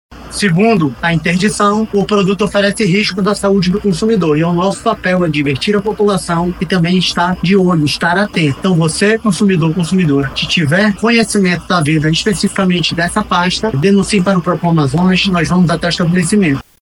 O diretor-presidente do Procon Amazonas, Jalil Fraxe, alerta consumidores e lojistas quanto a medida.